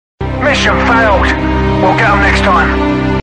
MISSION FAILED ringtone
Inicia con un sonido de alerta rápida y aguda, seguido por una pausa breve.
Voz robótica y firme:
Pequeña explosión digital con chisporroteo electrónico, seguida de un eco leve.
Sonido de reinicio de sistema, zumbido ascendente que se desvanece lentamente.
Término con un pitido suave, como un indicador de nueva misión o tarea.